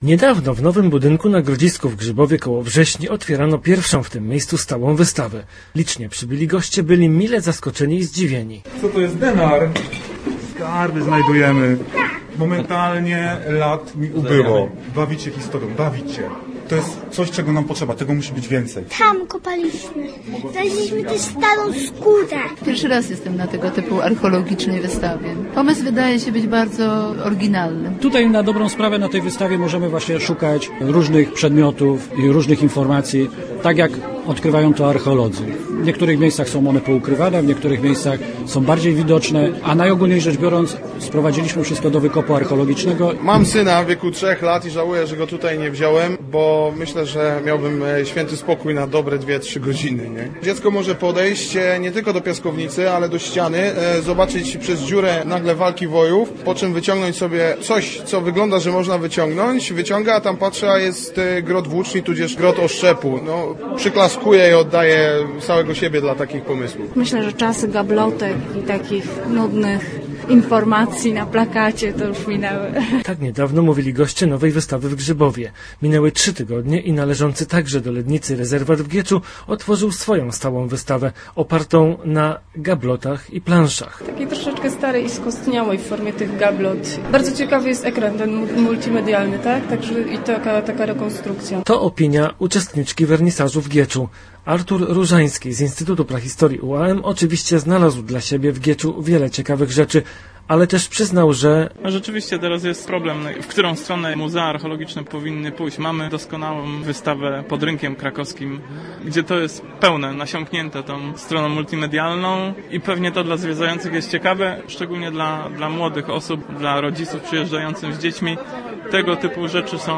Nasz reporter wybrał się do rezerwatu archeologicznego w Gieczu, gdzie otwarto nową stałą wystawę. 80% eksponatów to nowości, nie pokazywane na dotychczasowej stałej wystawie.